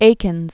(ākĭnz), Thomas 1844-1916.